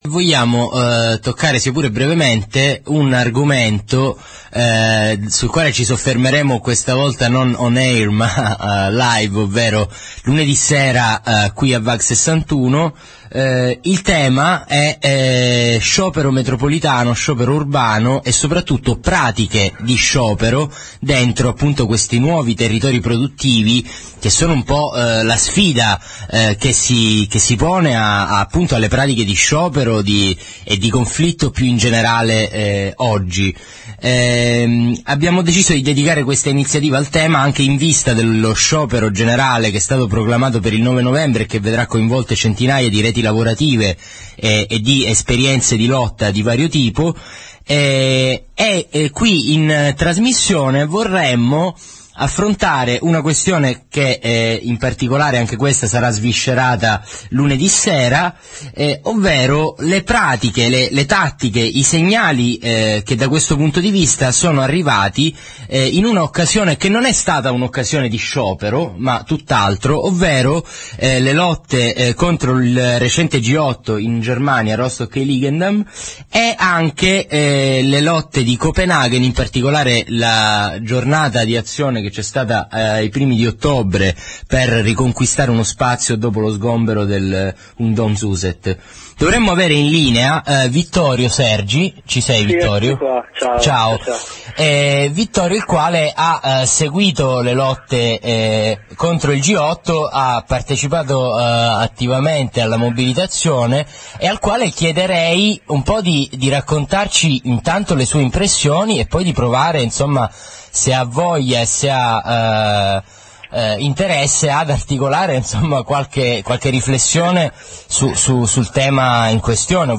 presentazione dell'iniziativa e intervista